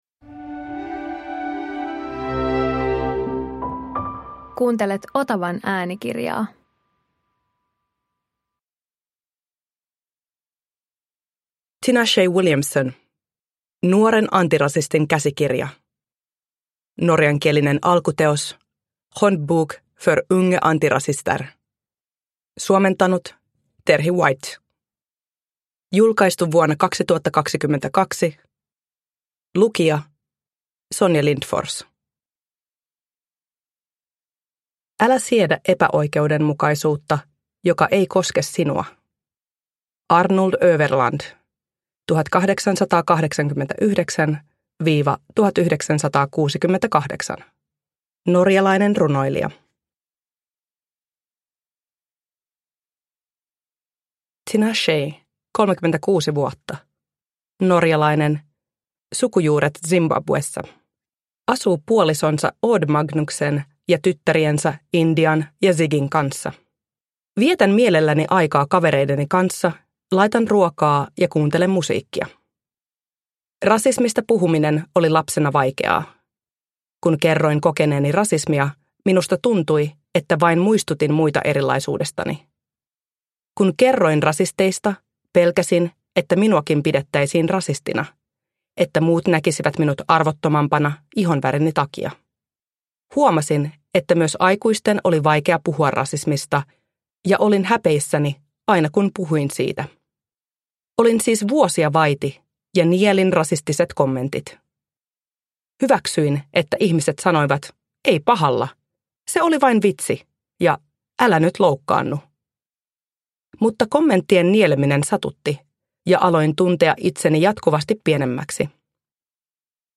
Nuoren antirasistin käsikirja – Ljudbok – Laddas ner